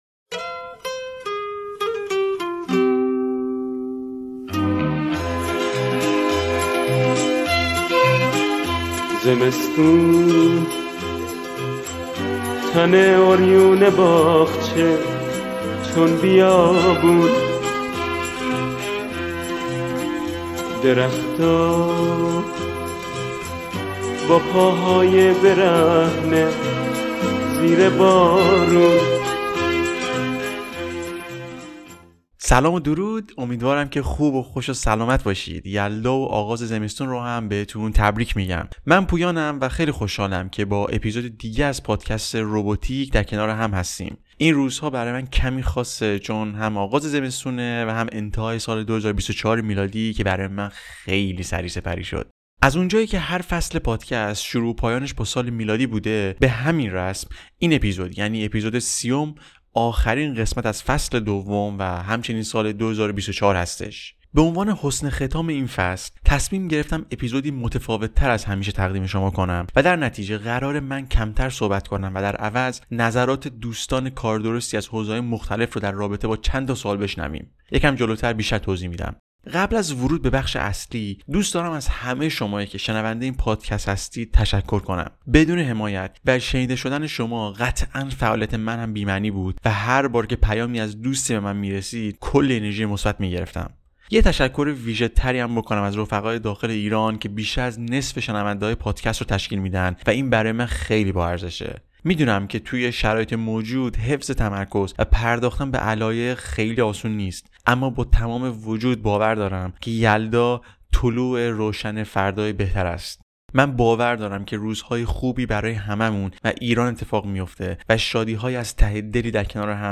به عنوان حُسن خِتام این فصل، تصمیم گرفتم اپیزودی متفاوت تر از همیشه تقدیم شما کنم و در نتیجه قراره من کمتر صحبت کنم و در عوض نظرات دوستان کاردرستی از حوزه های مختلف رو در رابطه با سه سوال بشنویم.